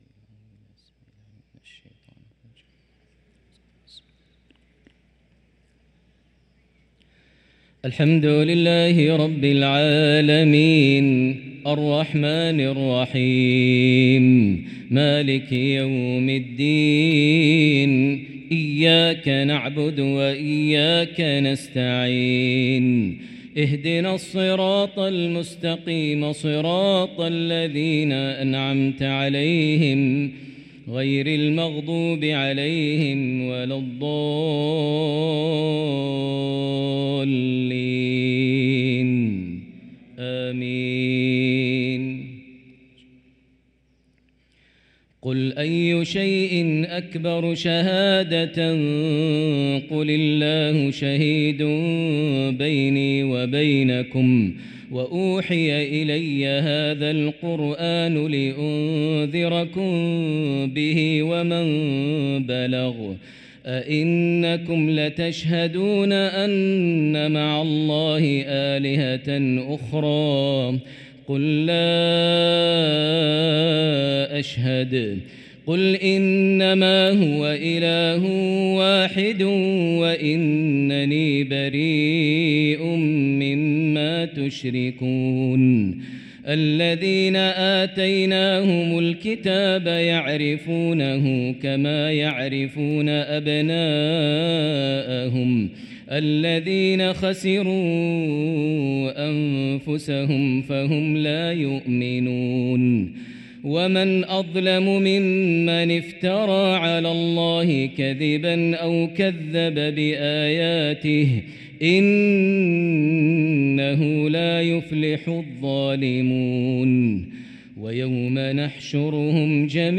صلاة العشاء للقارئ ماهر المعيقلي 29 ربيع الآخر 1445 هـ
تِلَاوَات الْحَرَمَيْن .